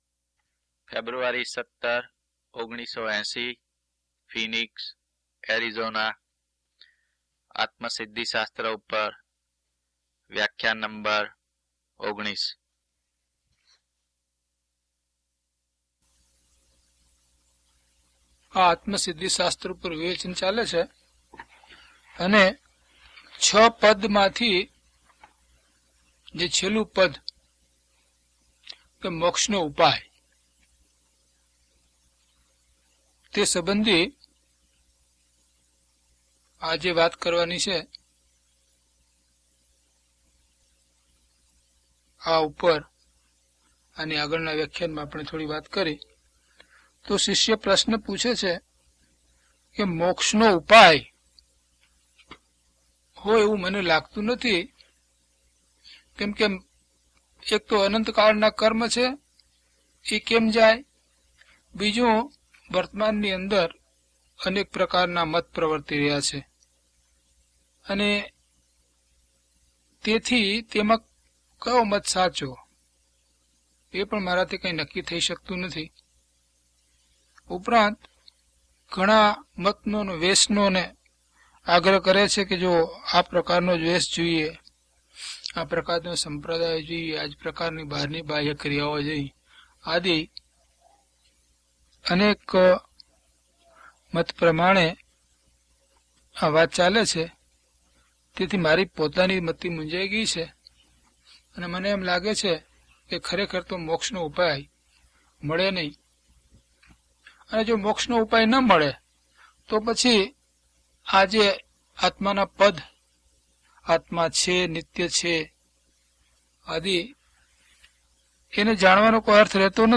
DHP028 Atmasiddhi Vivechan 19 - Pravachan.mp3